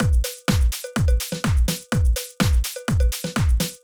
Drumloop 125bpm 03-A.wav